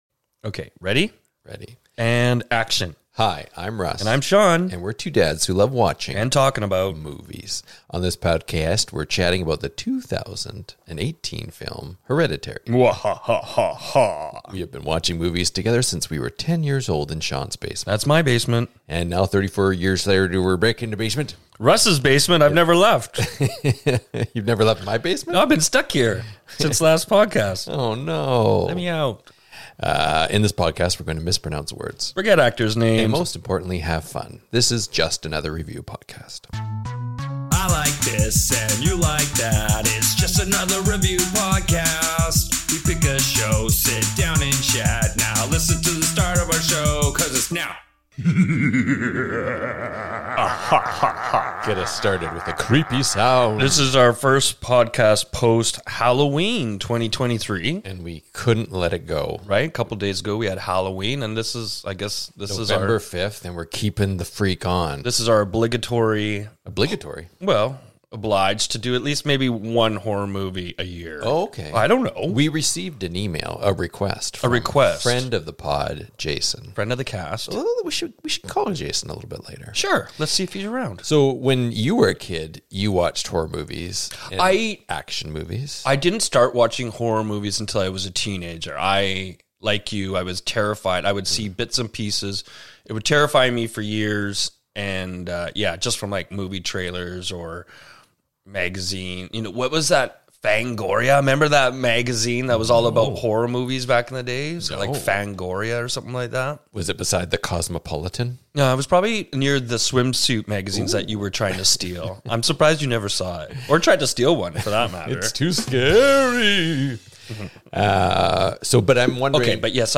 The two Acolytes mispronounce words, forget Actors names, and most importantly have fun.